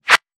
weapon_bullet_flyby_19.wav